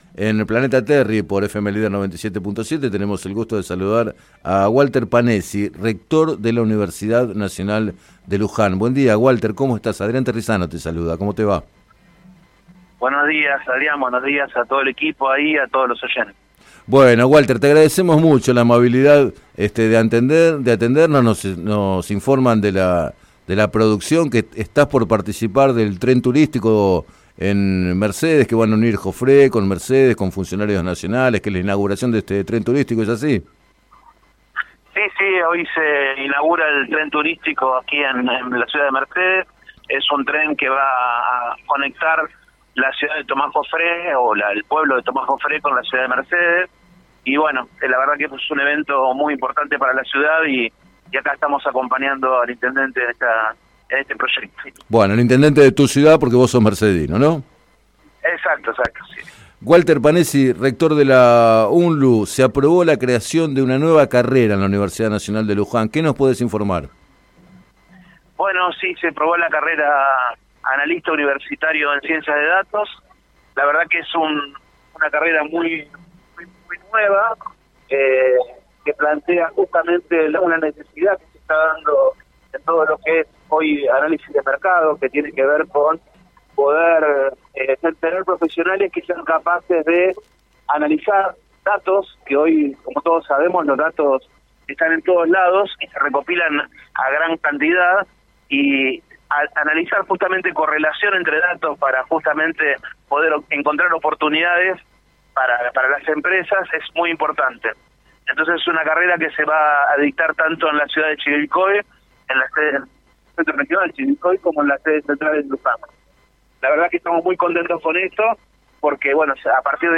En declaraciones al programa Planeta Terri de FM Líder 97.7, el rector de la Universidad, Walter Panessi, señaló que existe una necesidad de profesionales capaces de analizar datos para su correcto aprovechamiento e interpretación.